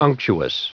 Prononciation du mot unctuous en anglais (fichier audio)
Prononciation du mot : unctuous